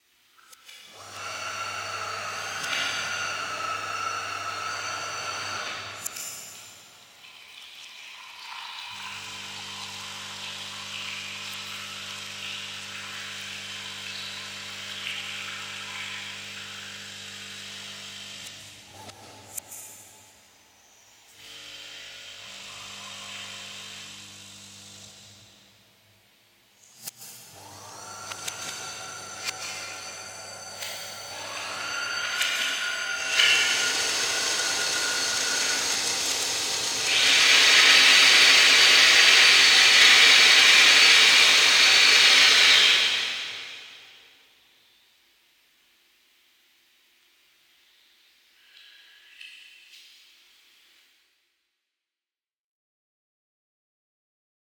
It’s experimental.
It's a headset thing. It ranges from field recordings to noise to 4-track dual mono sounds to whatever comes to mind.